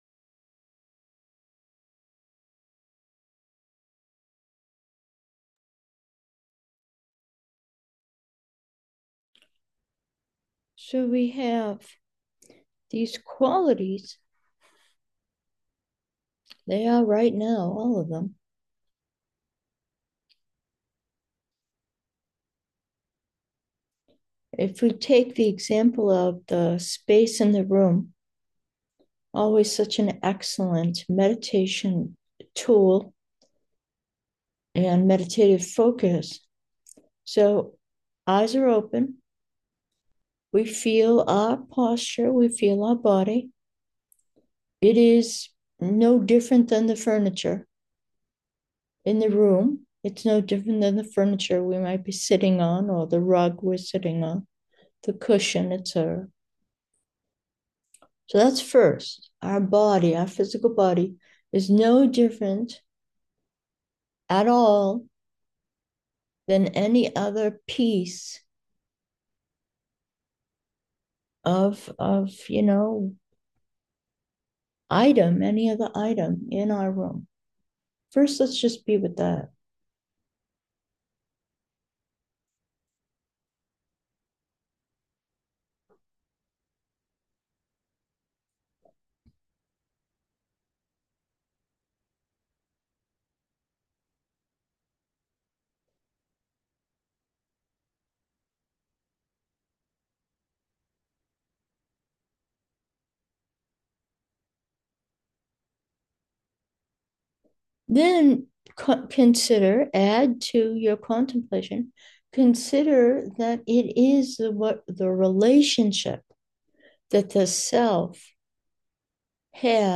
Meditation: relation